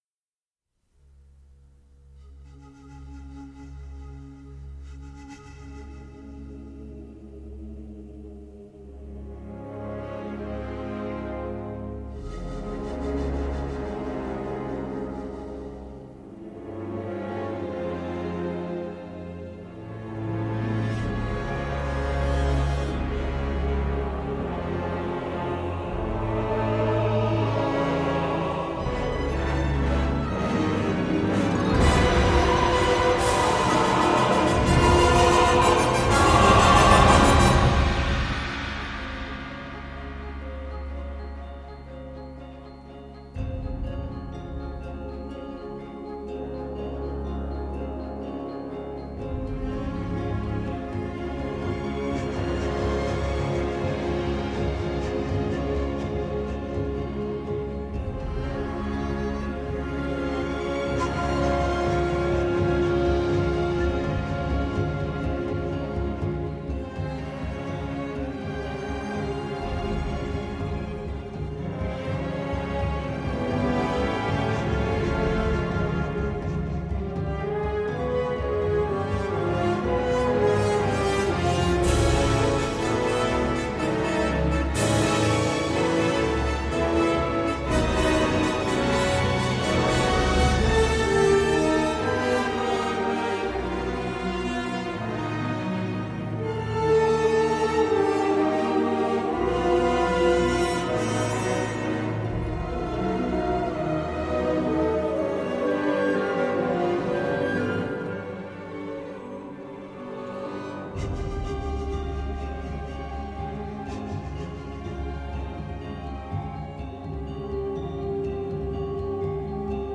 rythmes et couleurs déjantées
Une partition vénéneuse, généreuse et carrément jouissive.
Envoûtant.